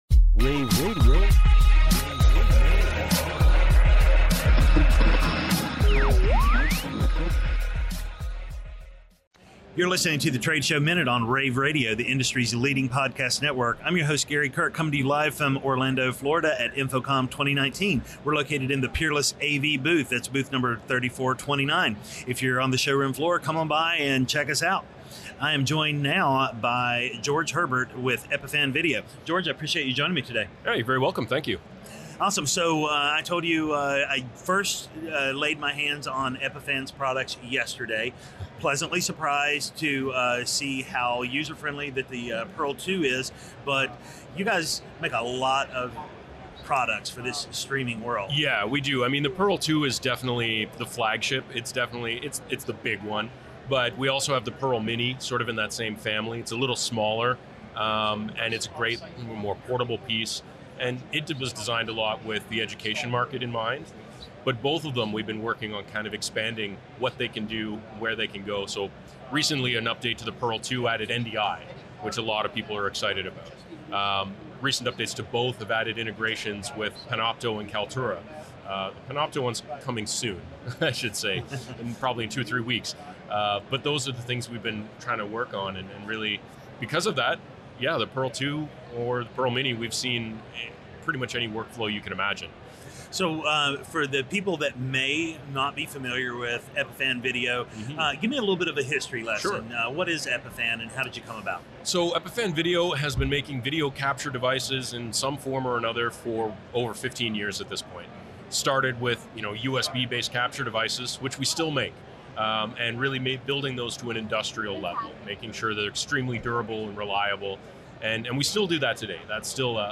June 13, 2019 - InfoComm, InfoComm Radio, Radio, The Trade Show Minute,